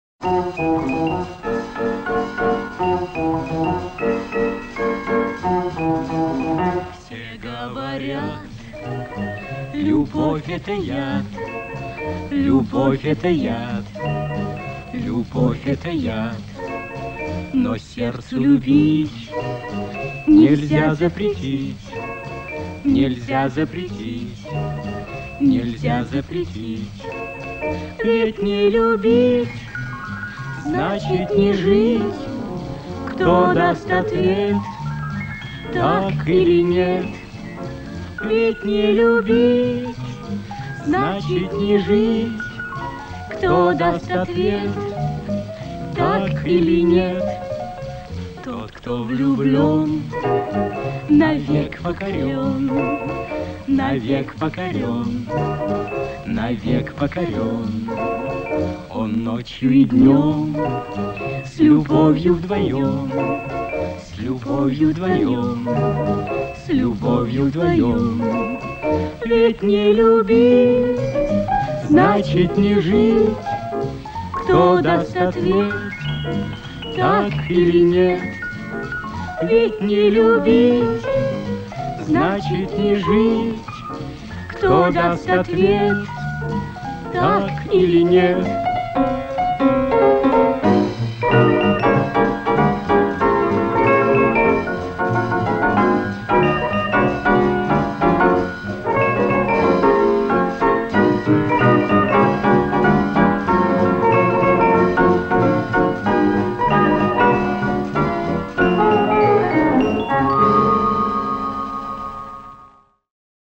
• эквалайзером подавлены высокие частоты